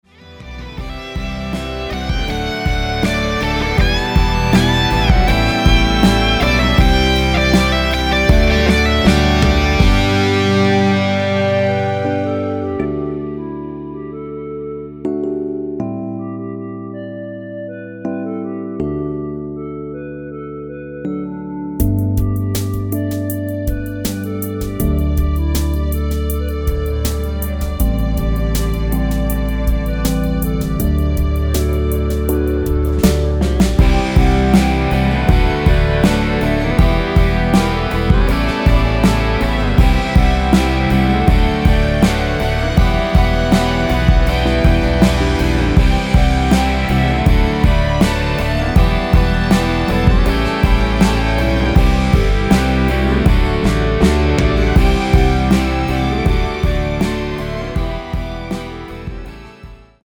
원키에서(-5)내린 멜로디 포함된 MR입니다.(미리듣기 확인)
앞부분30초, 뒷부분30초씩 편집해서 올려 드리고 있습니다.